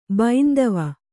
♪ baindava